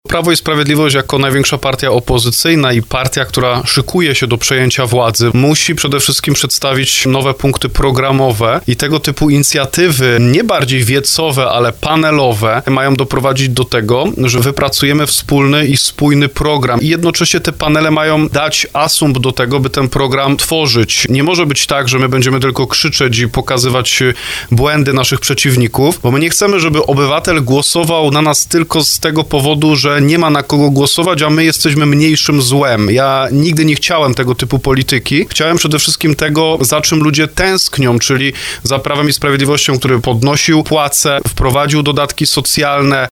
– Nie chcemy, żeby Polacy głosowali na Prawo i Sprawiedliwość jako mniejsze zło, tylko na konkretny program, który poprawi życie ludzi – mówił w porannej rozmowie RDN Małopolska poseł Norbert Kaczmarczyk.